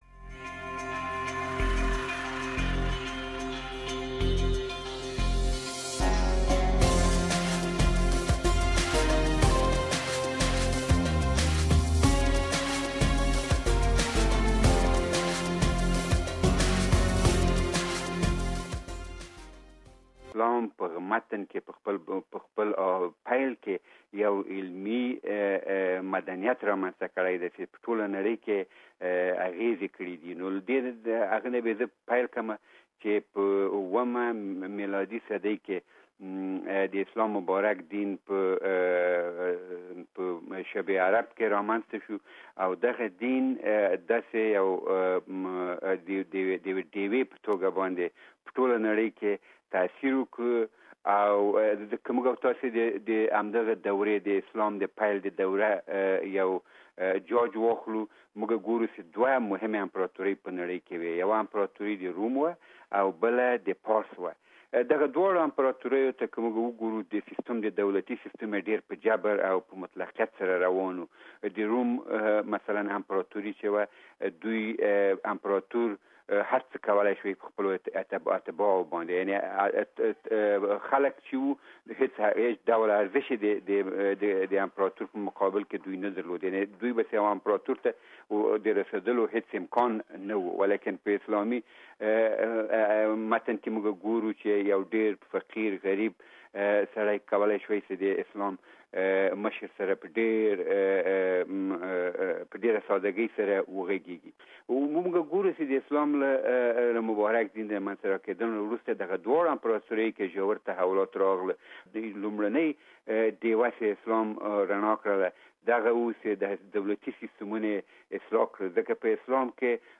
He has researched on Islam and the western civilization. You can listen to his exclusive interview with SBS Pashto program here.